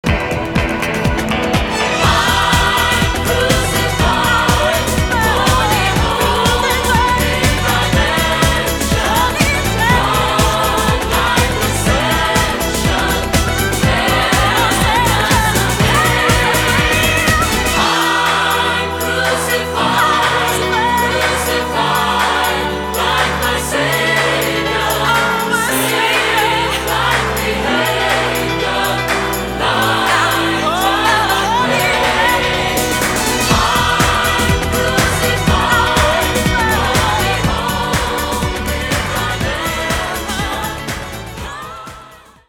• Качество: 320, Stereo
Synth Pop
90-е